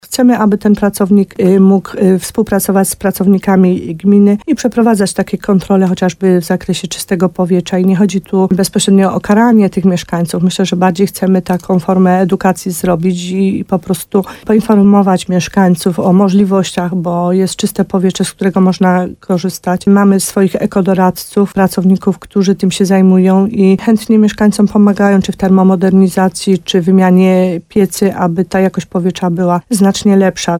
– mówiła w programie Słowo za Słowo w radiu RDN Nowy Sącz wójt gminy Podegrodzie, Małgorzata Gromala.
Rozmowa z Małgorzatą Gromalą: Tagi: Słowo za Słowo gmina Podegrodzie straż gminna Małgorzata Gromala Nowy Sącz